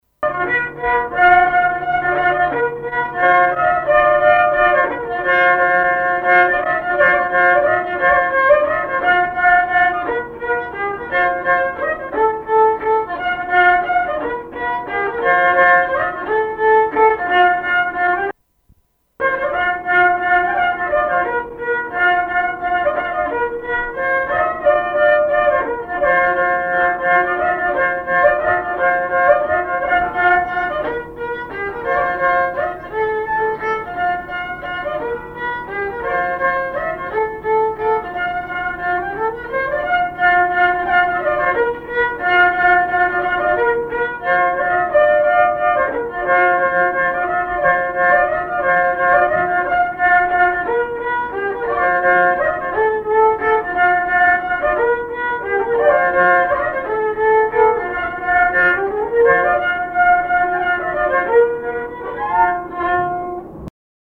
danse : scottich trois pas
Répertoire de musique traditionnelle
Pièce musicale inédite